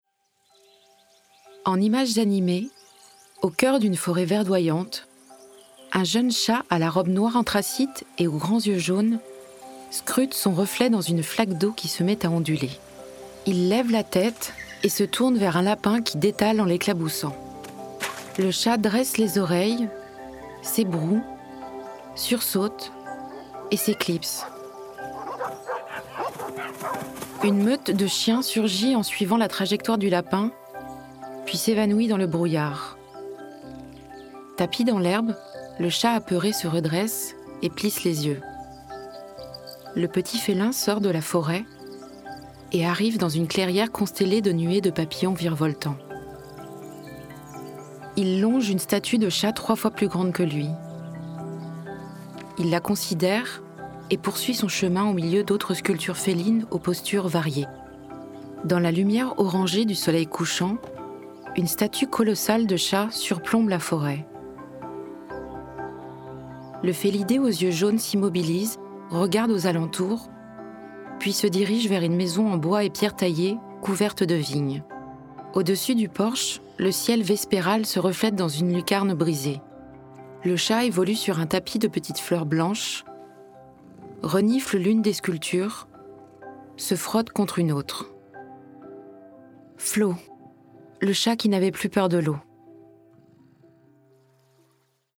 Ma voix est celle d’une femme entre trente et quarante ans qui a du coeur, de l’humanité, de la luminosité et une douceur qui convient par exemple très bien à la narration d’un drame historique.
Audio-description Cinéma de fantasy : Flow, le chat qui n’avait plus peur de l’eau de Gints Zilbalodis.